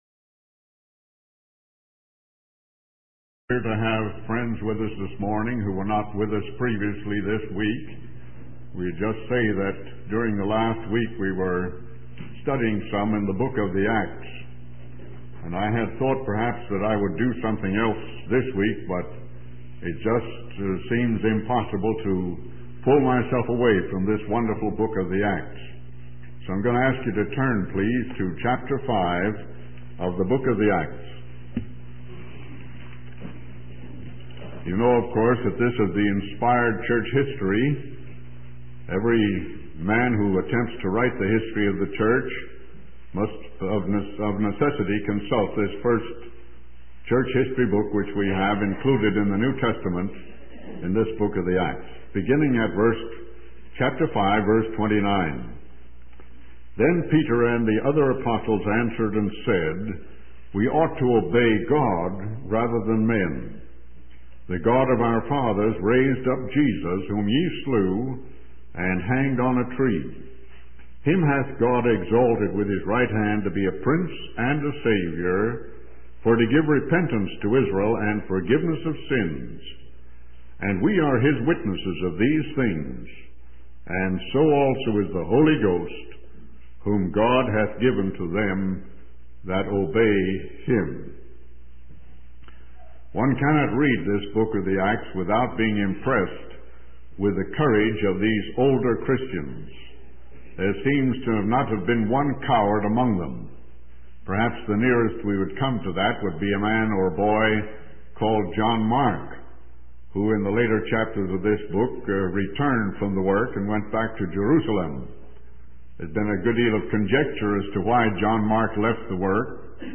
In this sermon, the speaker emphasizes three main points. Firstly, he highlights that the men preaching the word of God were subject to divine authority. Secondly, he emphasizes that their purpose was to present Jesus Christ as the central figure.